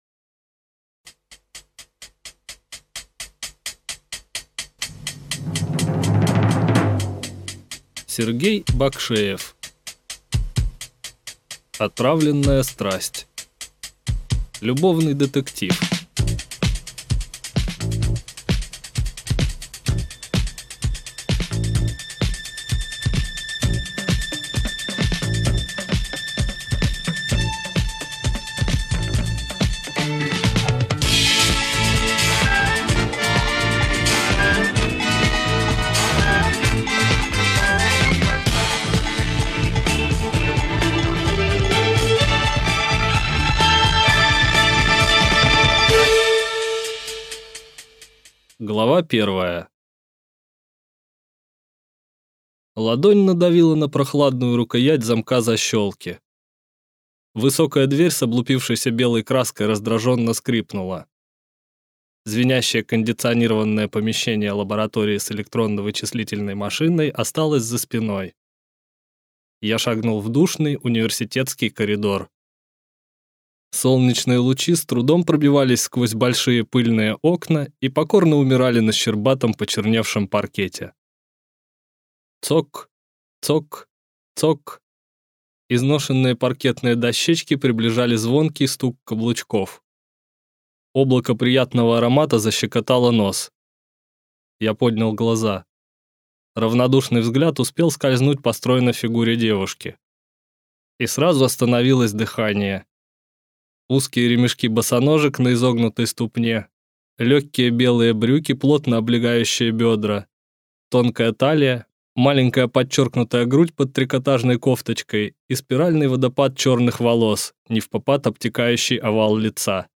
Аудиокнига Отравленная страсть | Библиотека аудиокниг